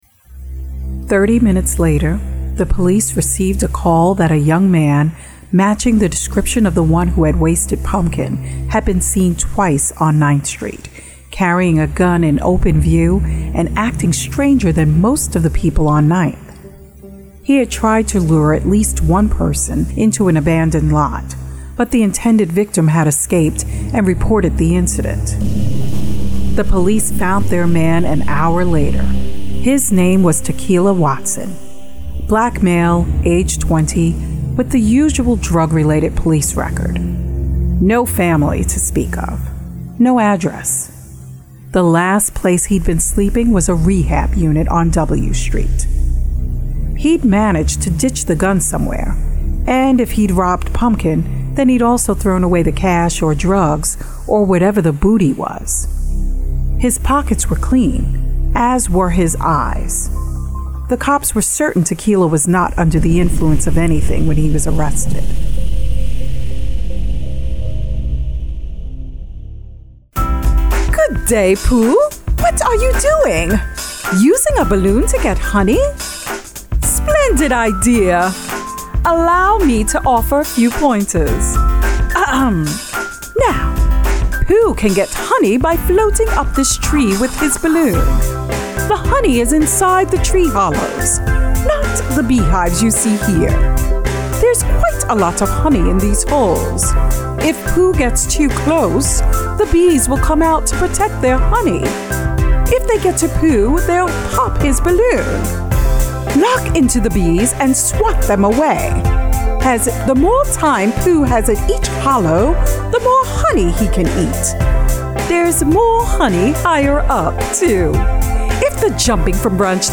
Commercial Demo